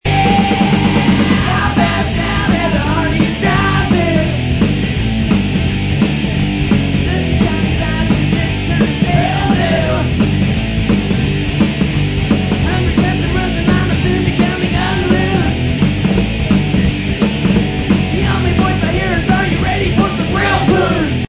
Original Music Samples With Vocals